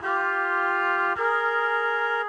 GS_MuteHorn_105-GBb.wav